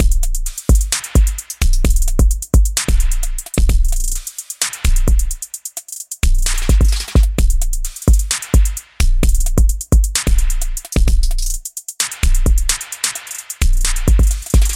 死亡的大鼓 130
描述：速度：130 bpm用其他循环试一下。
Tag: 130 bpm Hip Hop Loops Drum Loops 2.49 MB wav Key : Unknown